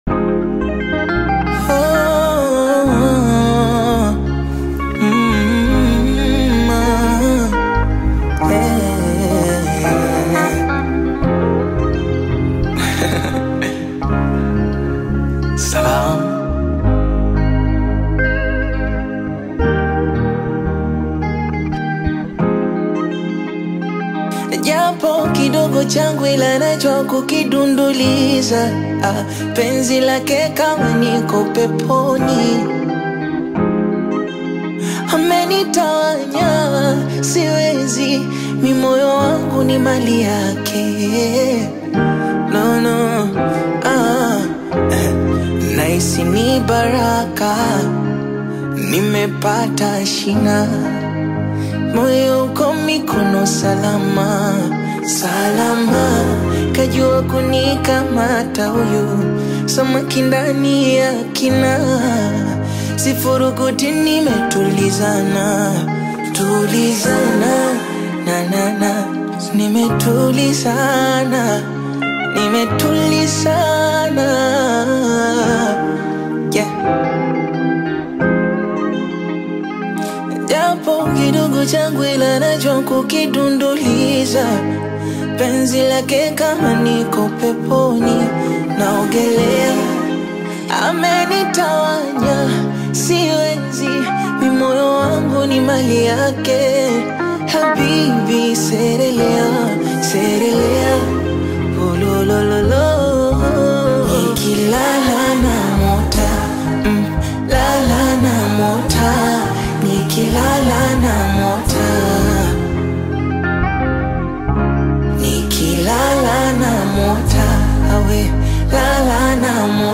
is a tender Bongo Flava/acoustic rendition
Genre: Bongo Flava